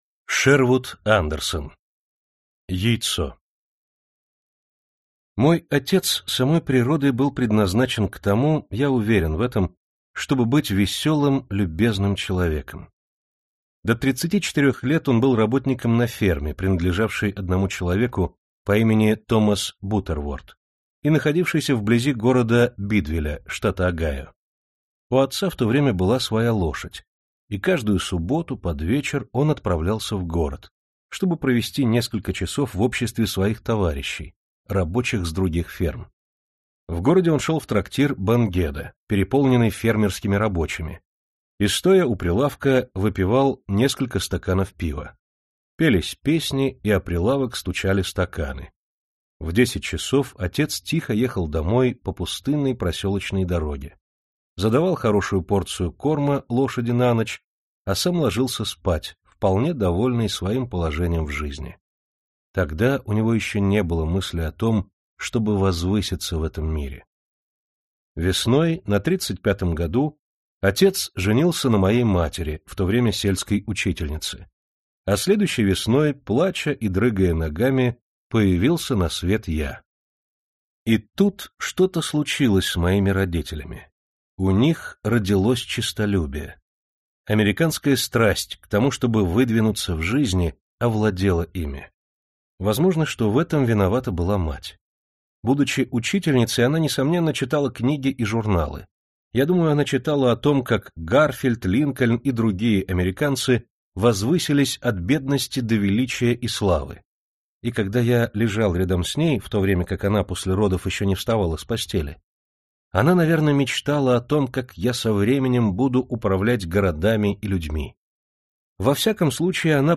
Аудиокнига Классика зарубежного рассказа № 19 | Библиотека аудиокниг